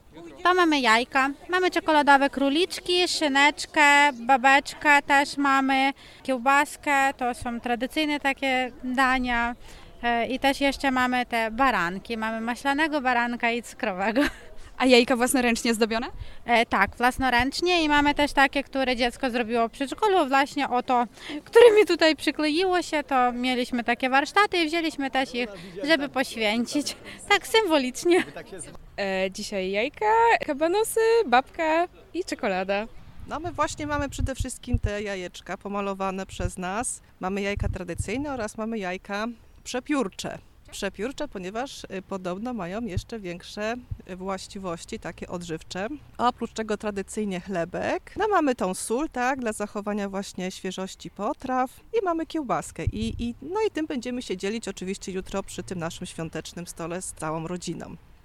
Pod kościołem spotkaliśmy rodziny z dziećmi, które zmierzały na poświęcenie pokarmów.
Dorośli również pozwolili nam zajrzeć do ich koszyczków.